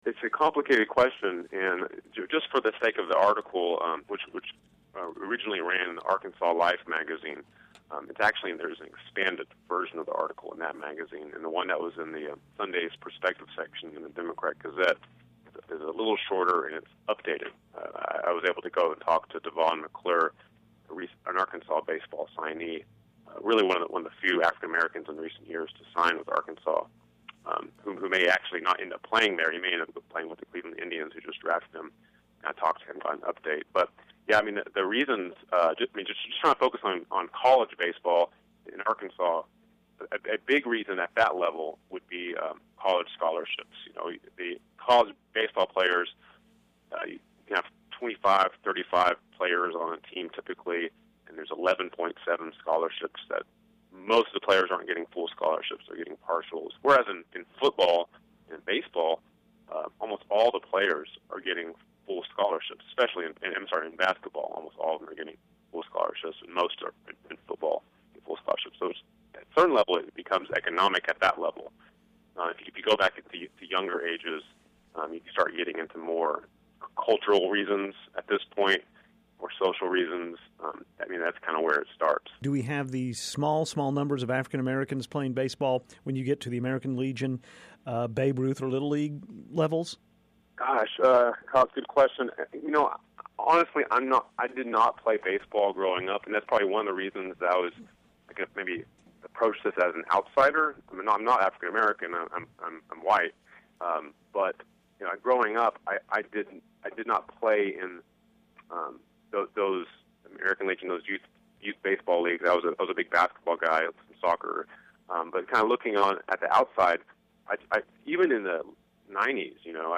speaks with freelance writer